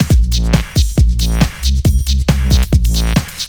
dooms_night_loop.wav